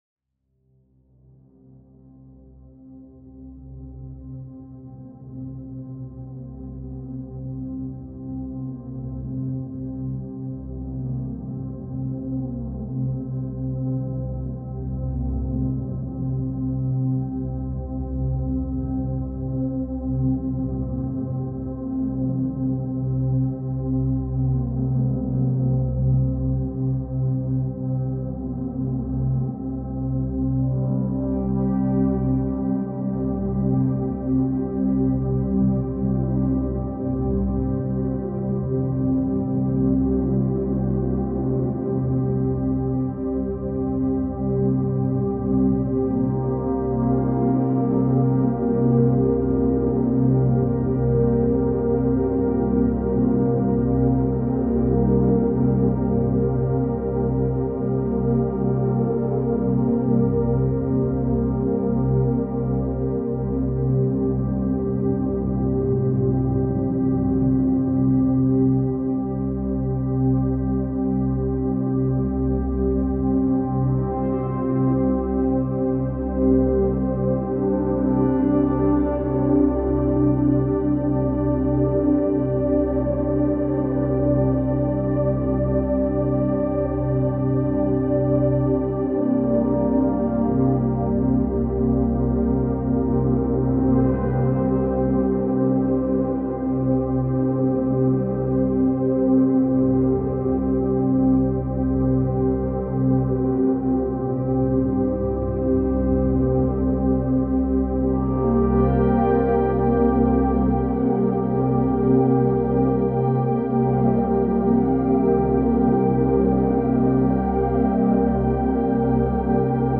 13 minutes Calm Relaxing Music sound effects free download
13 minutes Calm Relaxing Music and Sound.